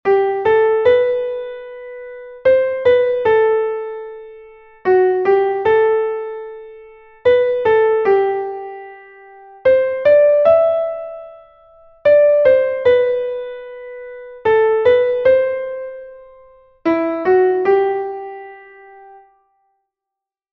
Here there are four six eight time signature exercises.